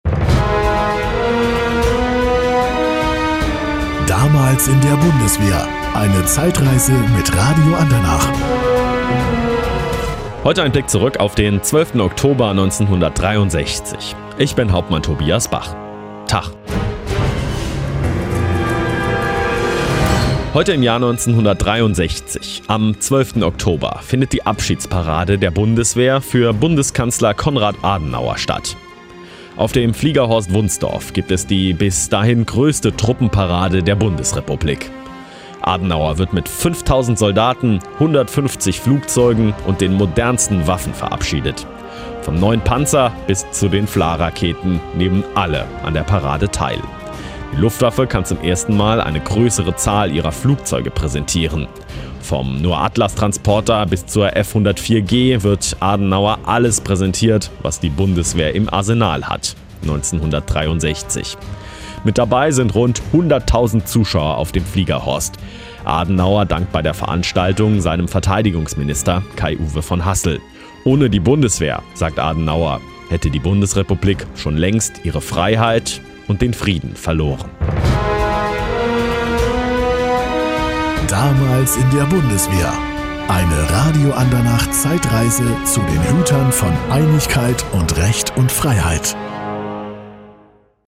Zur Verabschiedung von Bundeskanzler Konrad Adenauer fährt die Bundeswehr am 12. Oktober 1963 ganz groß auf: 5.000 Soldaten, 150 Flugzeuge und schweres Gerät aller Waffengattungen formieren sich zu der bis dahin größten Militärparade der noch jungen Truppe. Rund 100.000 Zuschauer verfolgen das Spektakel auf dem niedersächsischen Fliegerhorst Wunstorf live.